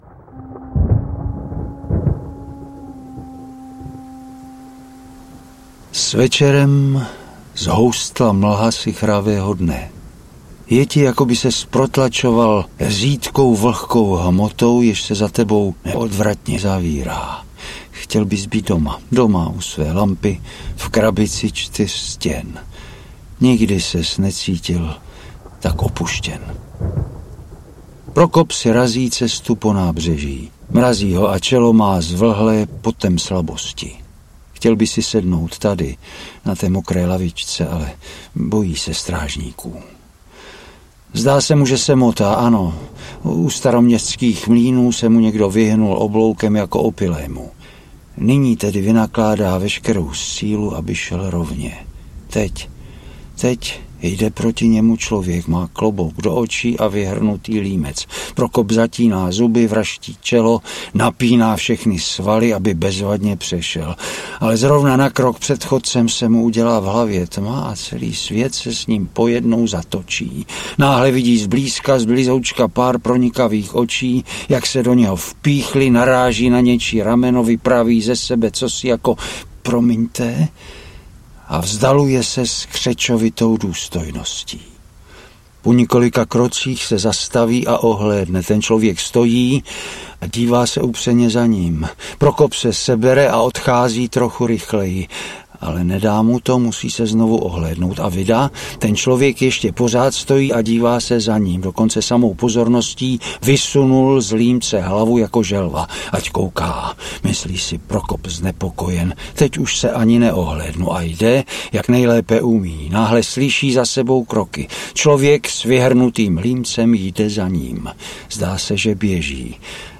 Krakatit audiokniha
Ukázka z knihy
• InterpretJiří Štědroň